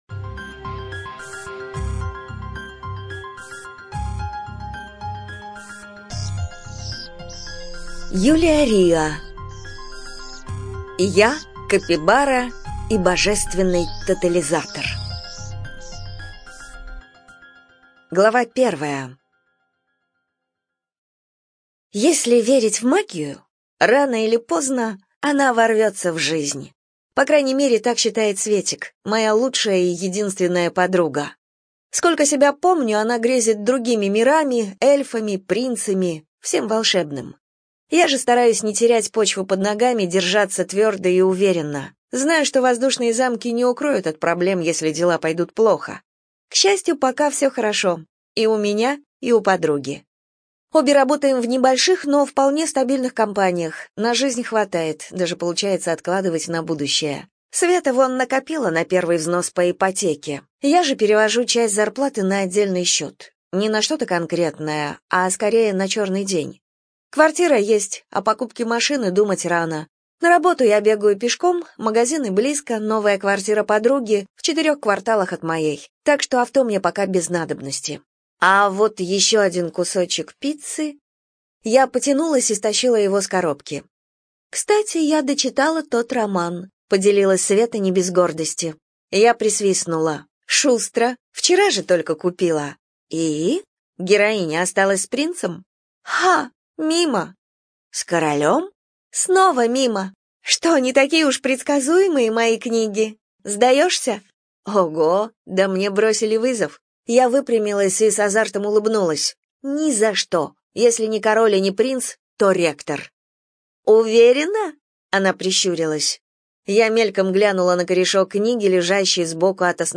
Жанр: Любовное фэнтези, попаданцы, юмористическое фэнтези